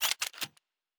Weapon 05 Reload 3 (Laser).wav